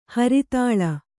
♪ haritāḷa